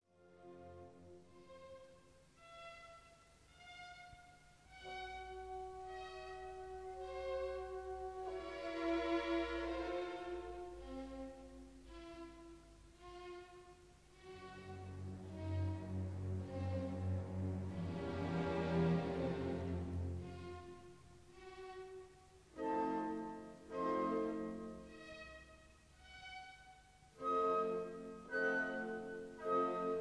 1960 stereo recording